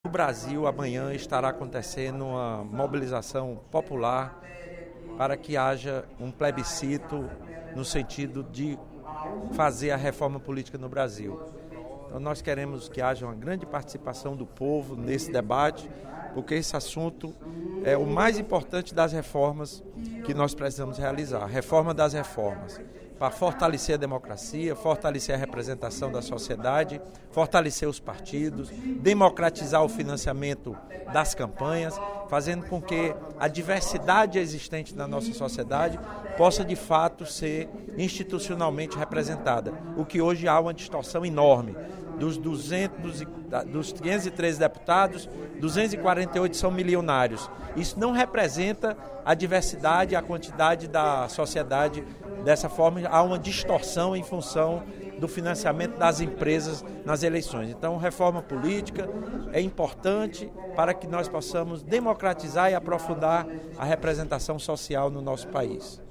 Durante o primeiro expediente da sessão plenária da Assembleia Legislativa desta quarta-feira (12/11), o deputado Lula Morais (PCdoB) destacou que, na quinta-feira, acontecerá o Dia Nacional de Luta pelo Plebiscito Constituinte em prol da Reforma Política no Brasil.
Em aparte, o deputado Dedé Teixeira (PT) reforçou a importância da mobilização das entidades para uma pressão legítima no Congresso Nacional em prol da reforma política, “sob pena de que haja distorção”.